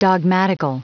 Prononciation du mot dogmatical en anglais (fichier audio)
Prononciation du mot : dogmatical